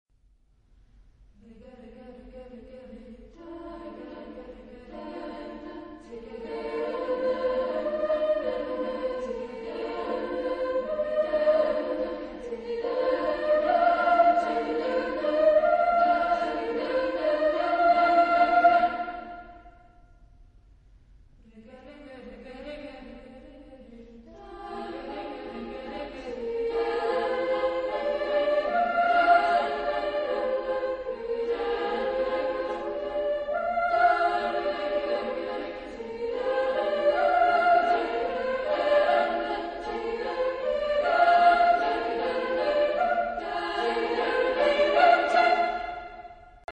Text in: vocalization
Genre-Style-Form: Vocal piece ; Secular
Type of Choir: SAAA  (4 women voices )
Tonality: free tonality